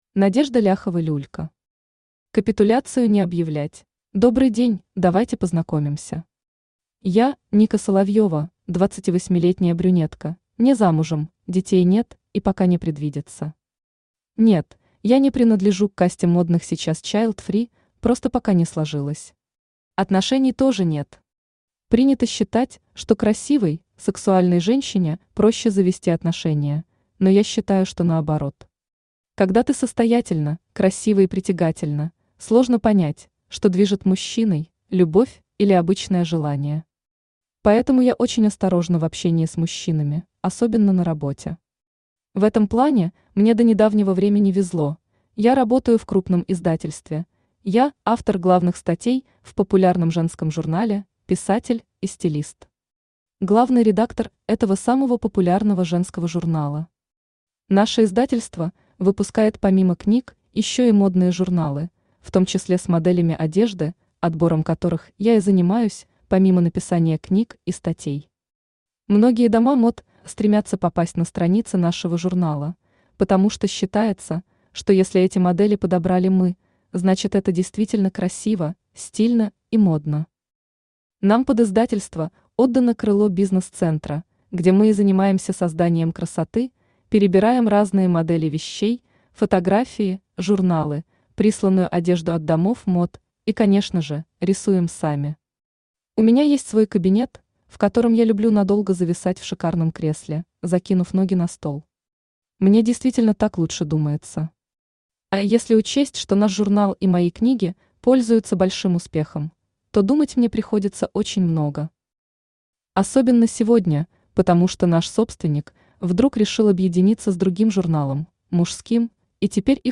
Аудиокнига Война в отдельно взятом офисе | Библиотека аудиокниг
Aудиокнига Война в отдельно взятом офисе Автор Надежда Викторовна Ляхова-Люлько Читает аудиокнигу Авточтец ЛитРес.